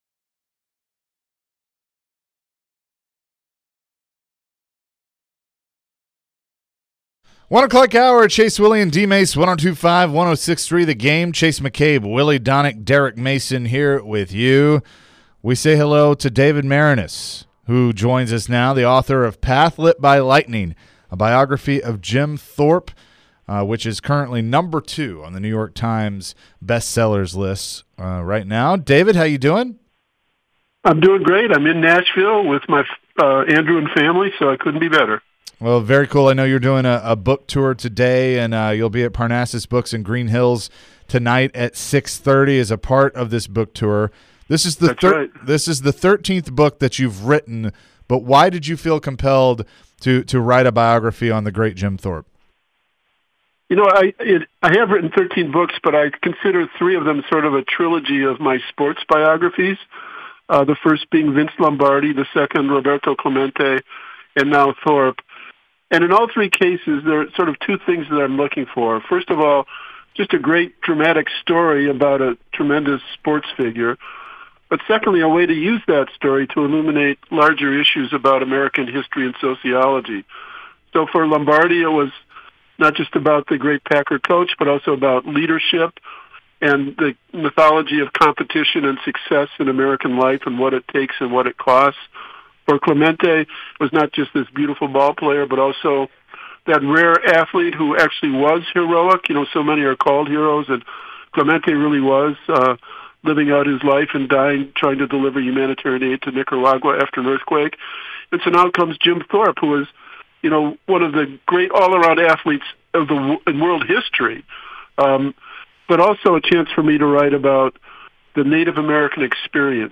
David Maraniss Full Interview (08-25-22)